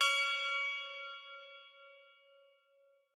bell1_10.ogg